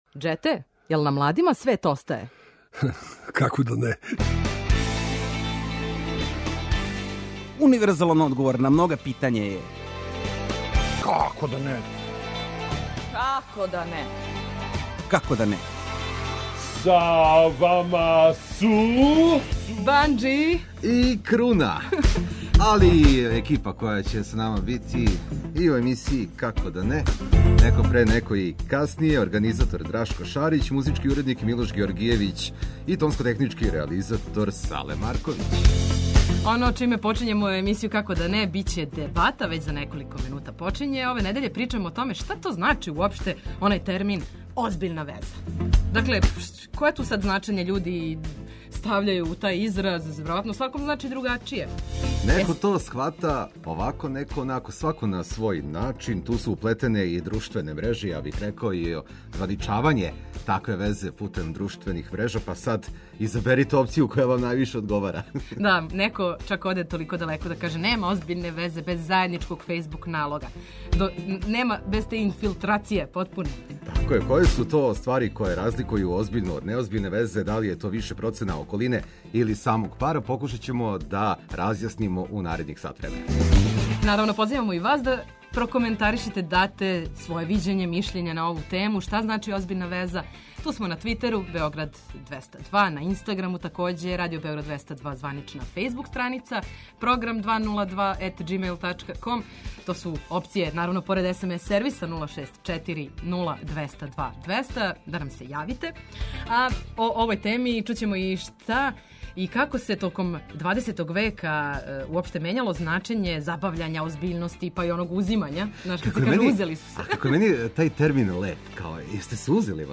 Окосница емисије је "Дебата" у којој ћемо разменити мишљења на свакодневне теме и дилеме. Ове недеље, причамо о томе шта значи - озбиљна веза?